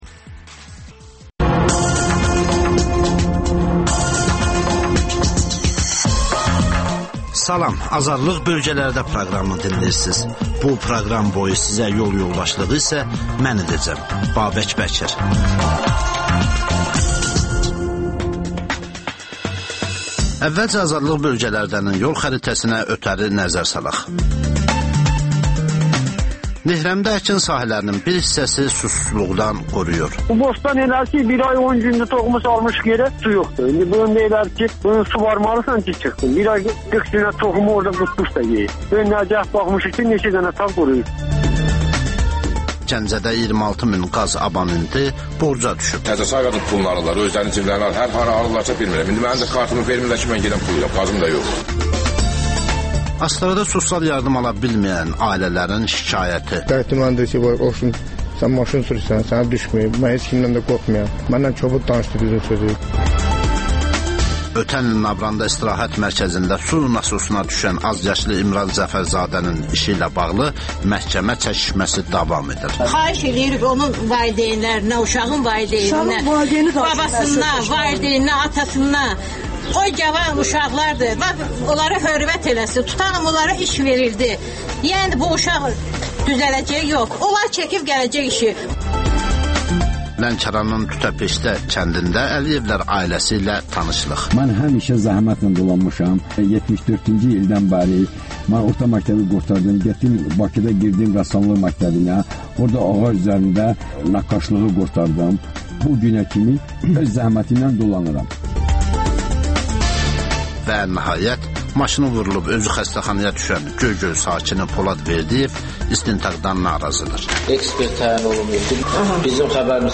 Rayonlardan xüsusi reportajlar.